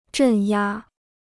镇压 (zhèn yā): suppression; repression.